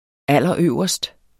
Udtale [ ˈalˀʌˈøwˀʌsd ]